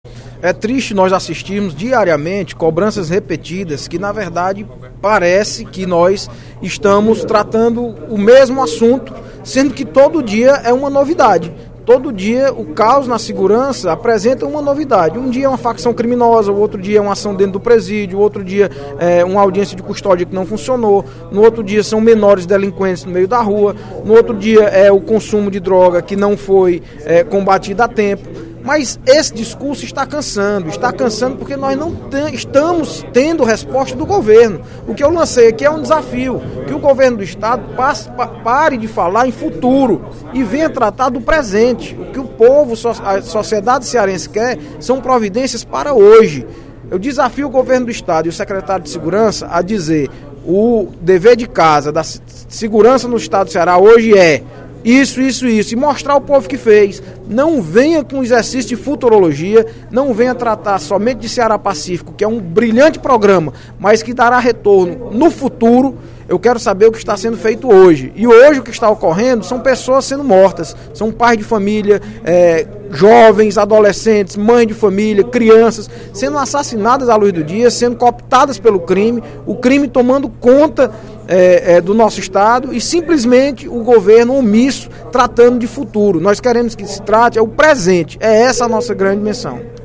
O deputado Audic Mota (PMDB) fez pronunciamento nesta quarta-feira (09/03), no primeiro expediente da sessão plenária, para cobrar ações efetivas para coibir a criminalidade.